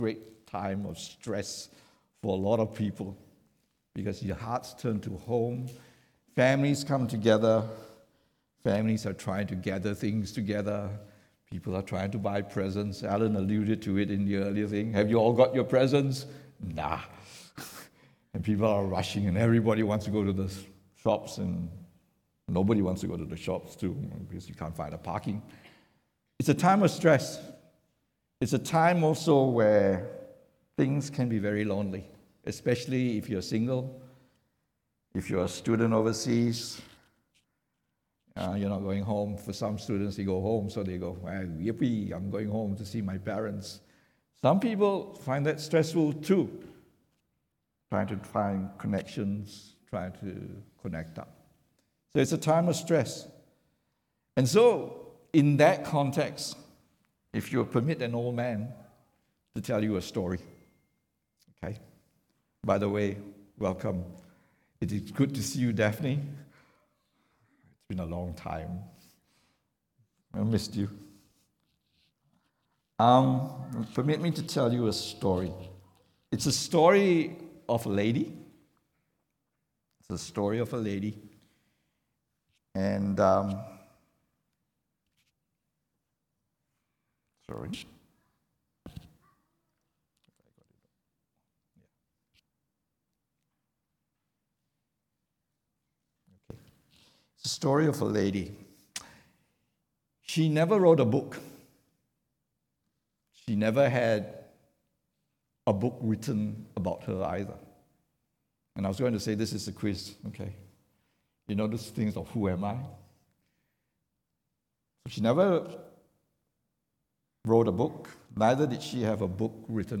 English Sermons | Casey Life International Church (CLIC)
English Worship Service - 4th December 2022